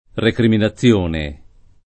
[ rekrimina ZZL1 ne ]